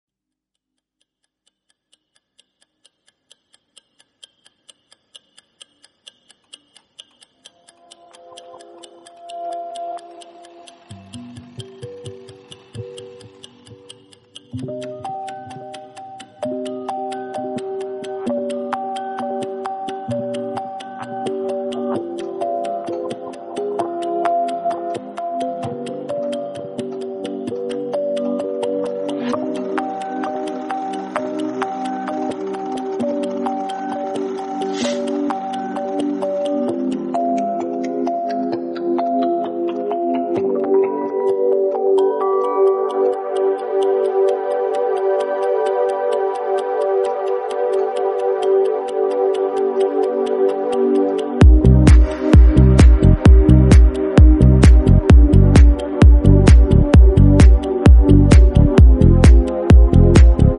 спокойные
без слов
тиканье часов
колыбельные